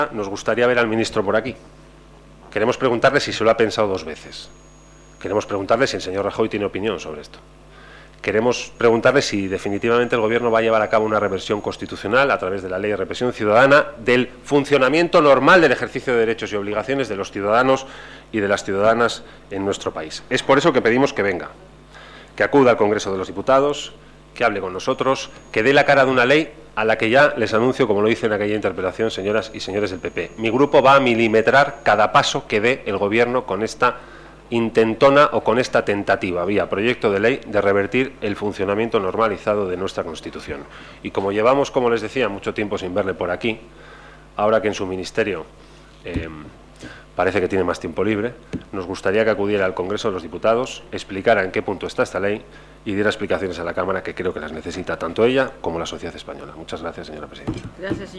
Eduardo Madina. Diputación Permanente el 14/01/2014. Petición de comparecencia del Ministro del Interior sobre la ley de seguridad ciudadana